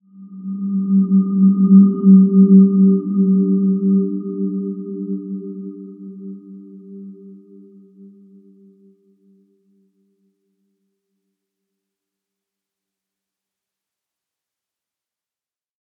Dreamy-Fifths-G3-p.wav